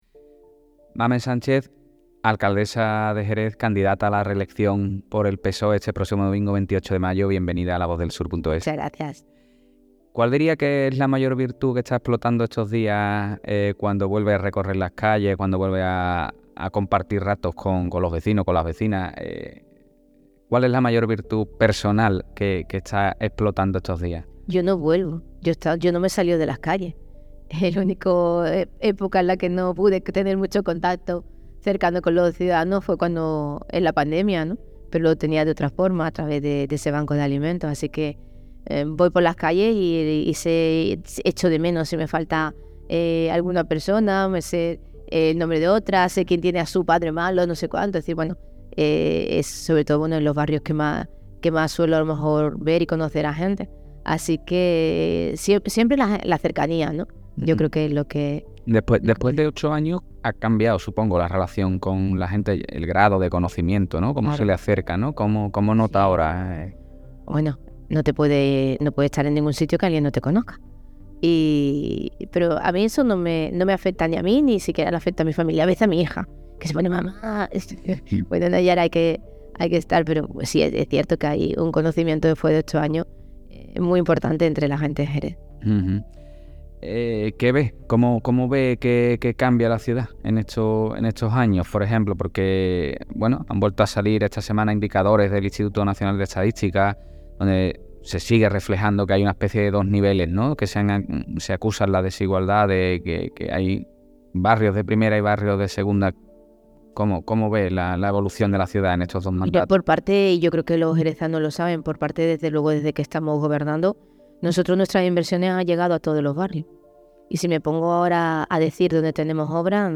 Escucha el audio con la entrevista íntegra con la alcaldable del PSOE, Mamen Sánchez